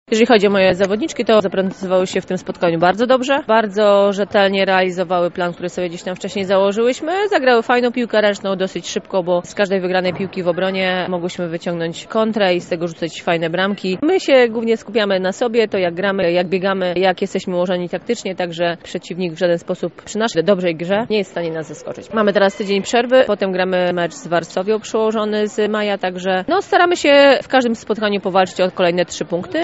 Tak po spotkaniu wypowiadała się